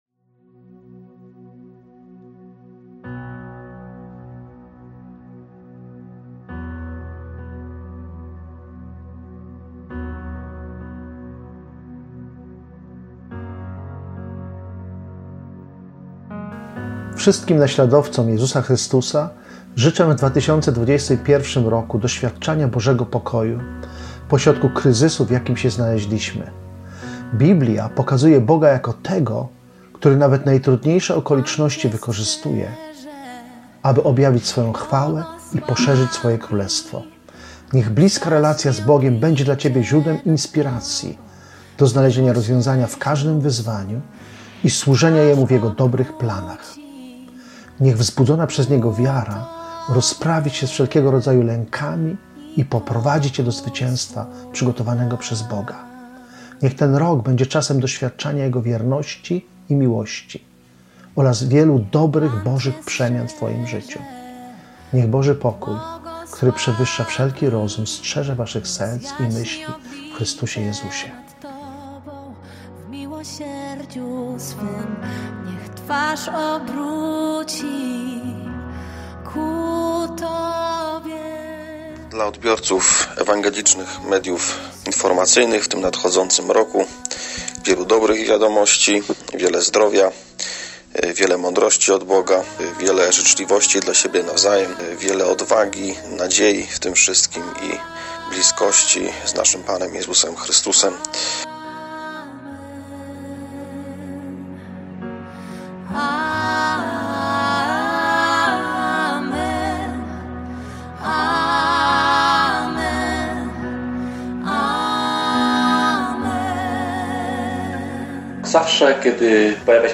Polscy Pastorzy, przywódcy różnych denominacji chrześcijańskich nagrali specjalnie dla Was życzenia błogosławieństwa na ten rozpoczęty właśnie 2021 rok.
Źródło: W materiale wykorzystano piosenkę „Błogosławieństwo, wyk. Exodus 15”